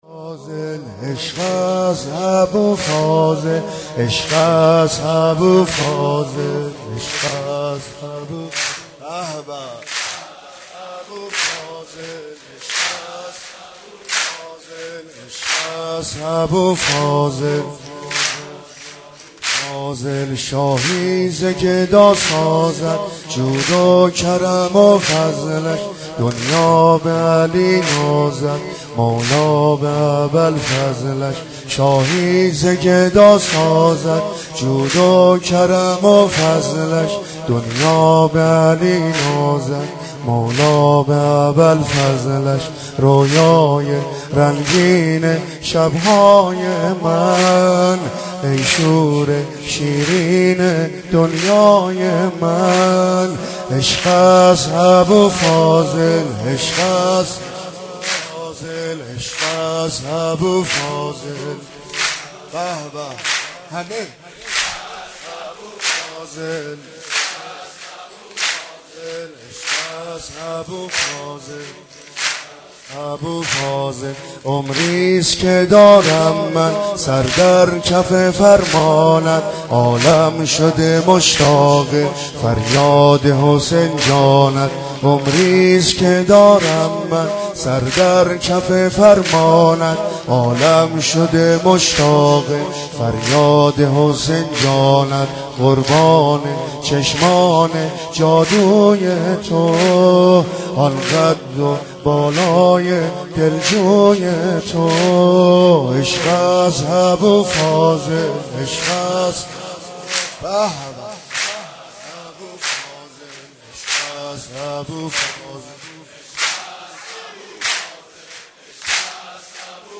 مولودی حاج عبدالرضا هلالی به مناسبت میلاد با سعادت حضرت ابالفضل العباس (ع)